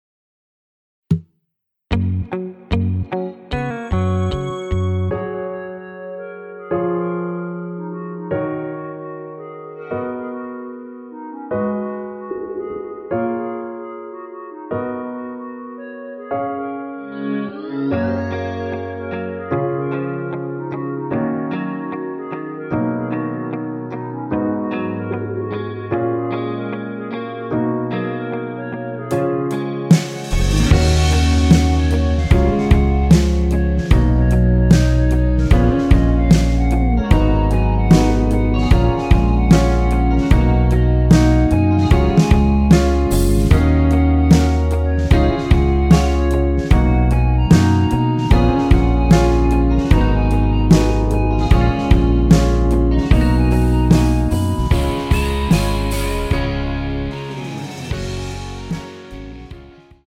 원키 멜로디 포함된 MR입니다.(미리듣기 확인)
Gb
앞부분30초, 뒷부분30초씩 편집해서 올려 드리고 있습니다.
중간에 음이 끈어지고 다시 나오는 이유는